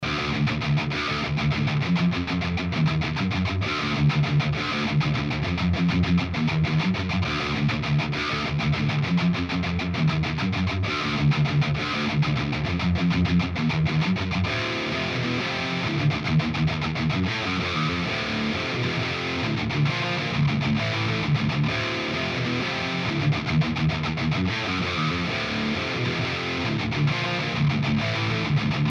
�������� ����� (tone matching), �������� ���� Mesa Rectifier